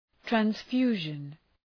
Προφορά
{træns’fju:ʒən}